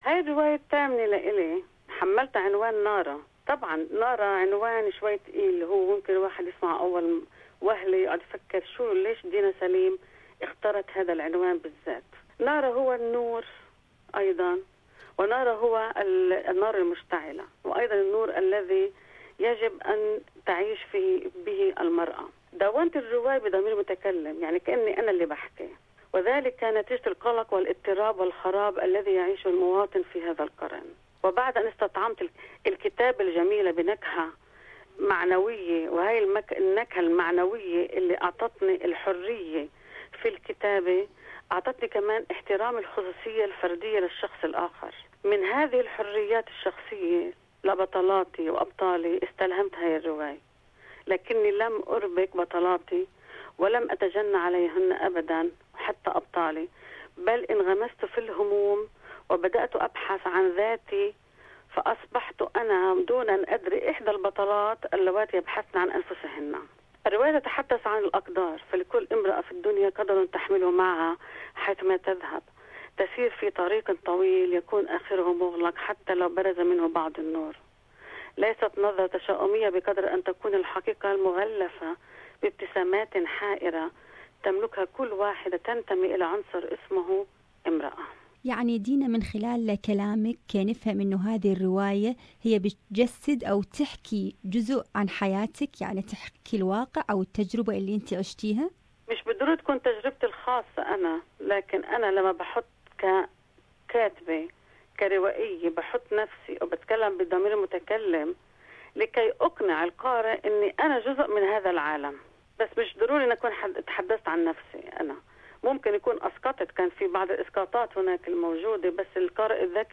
اذن المزيد عن هذه الرواية في المقابلة